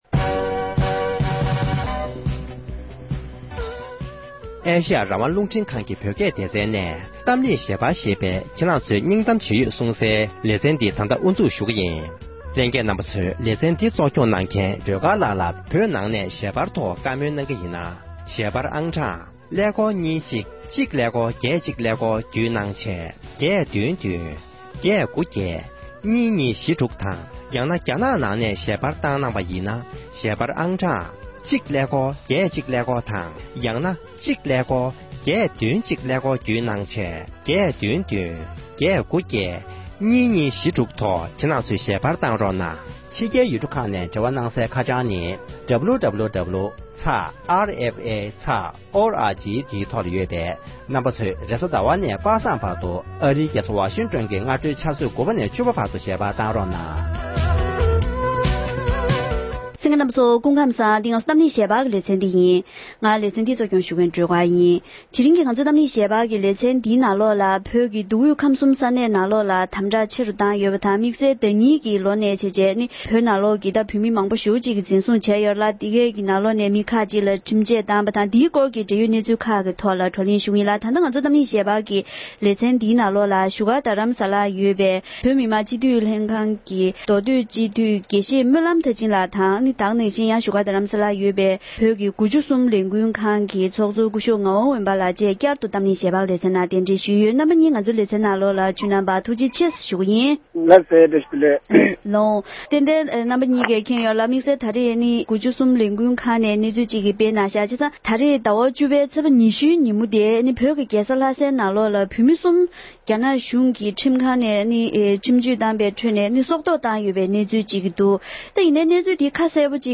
འབྲེལ་ཡོད་མི་སྣ་ཁག་དང་ལྷན་དུ་བགྲོ་གླེང་ཞུས་པ་ཞིག་ལ་གསན་རོགས༎